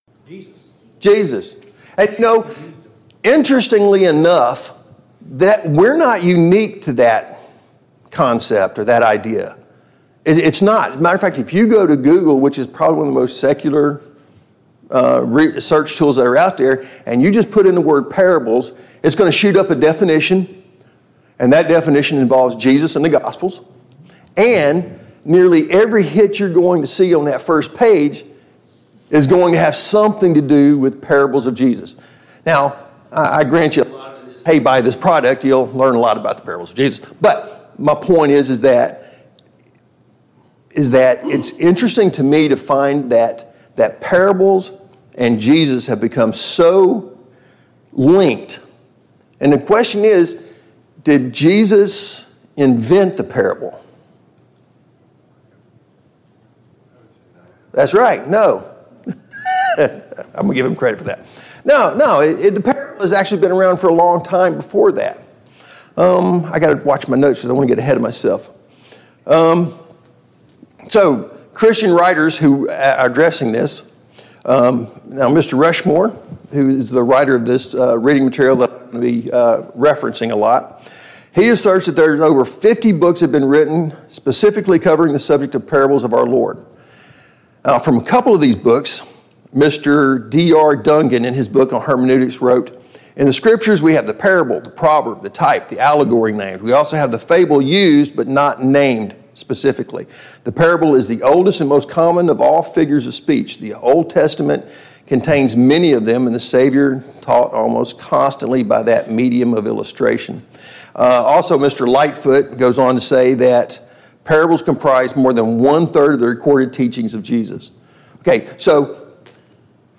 Series: Study on the Parables Service Type: Sunday Morning Bible Class « 67.